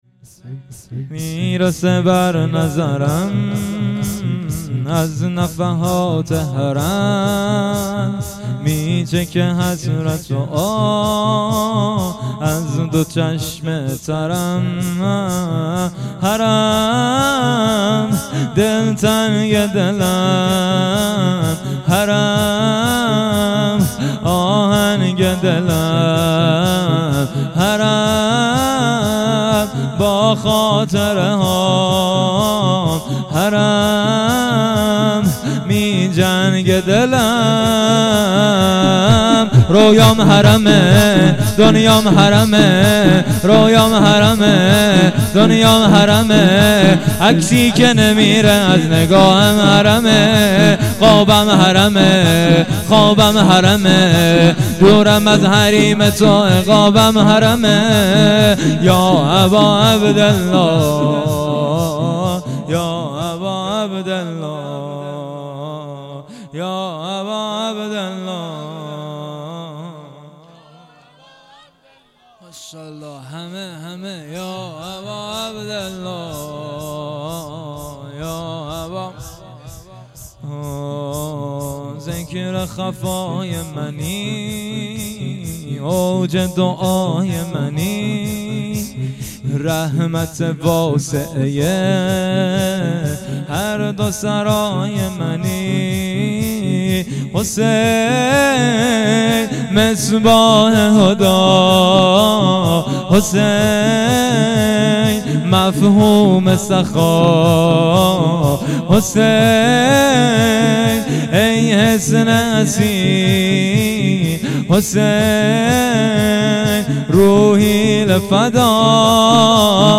0 0 شور
شب پنجم - دهه اول محرم 1400